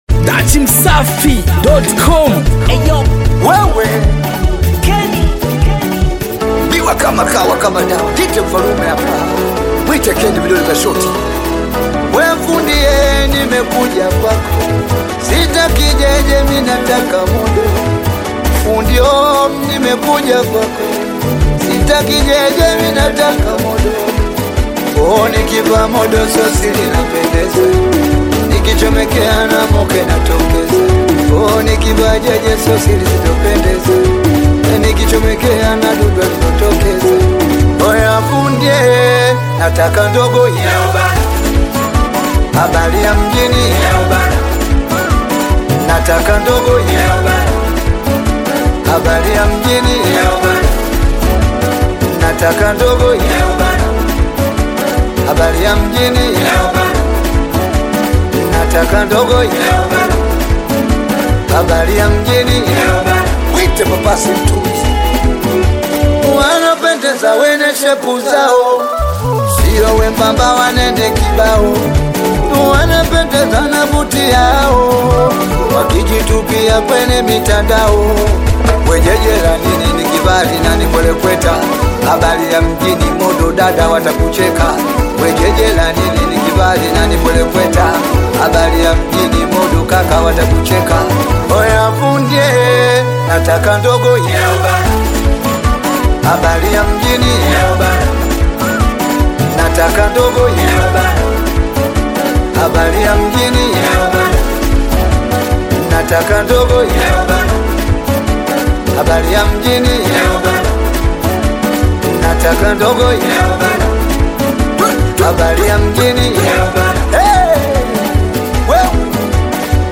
Audio, Singeli